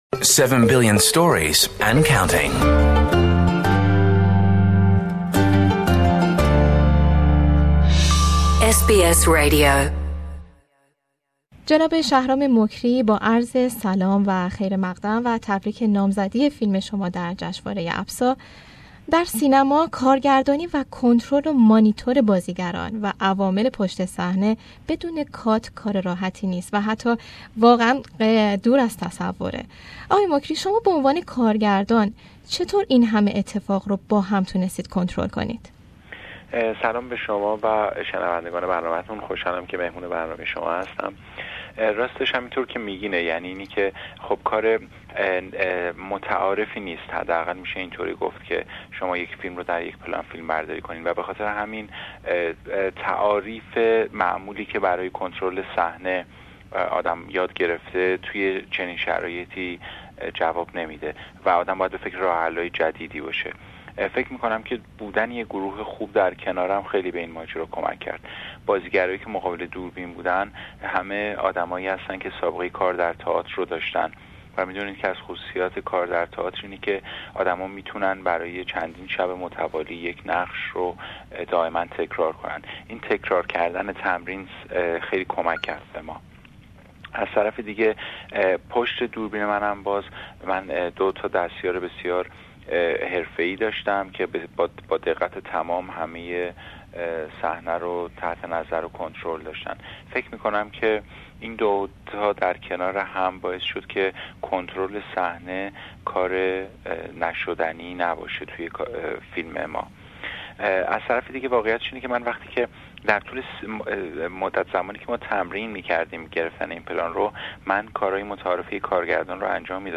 این فیلم نامزد دریافت جایزه در بخش بهترین کارگردانی شده بود اما نتوانست تا این جایزه را از آن خود کندشهرام مکری، کارگردان فیلم ماهی و گربه در گفتگو با بخش فارسی رادیو اس بی اس از این فیلم و کارهای هنری خود می گوید